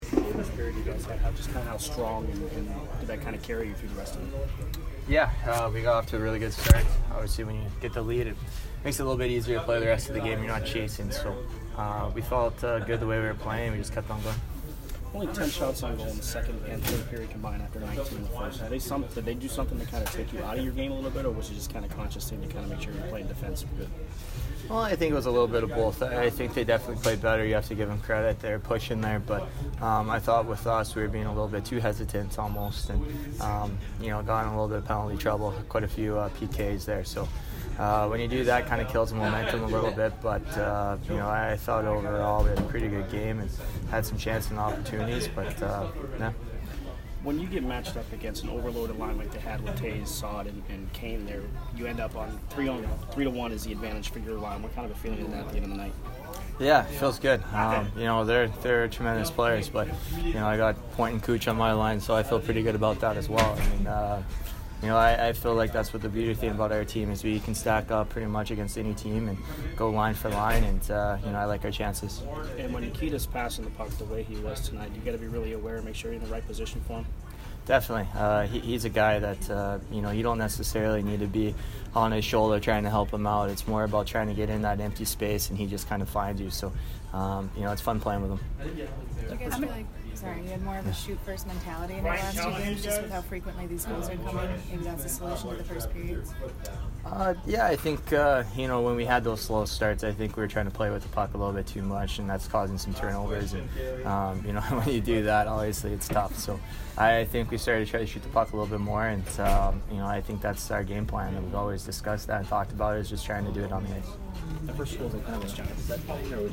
Tyler Johnson post-game 11/23